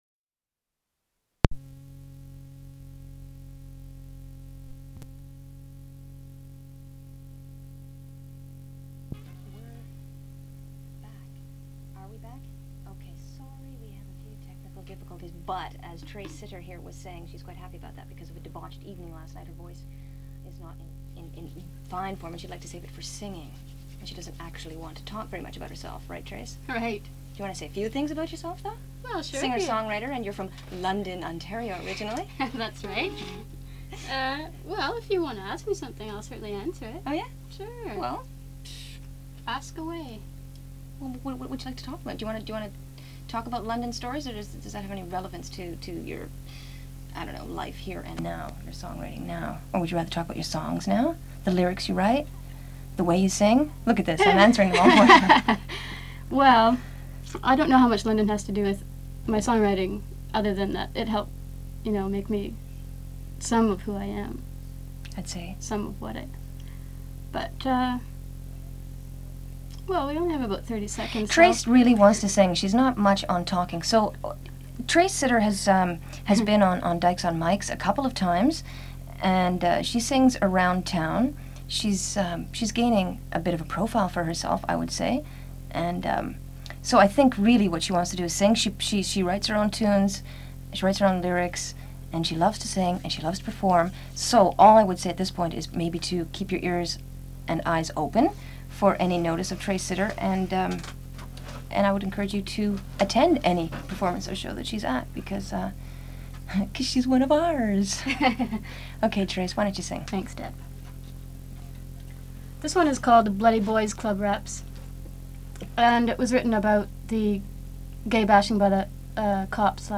Gay Day was an annual 24 hour broadcast event held by CKUT Radio (which hosted the Dykes on Mykes broadcast), from 1989 to 1991.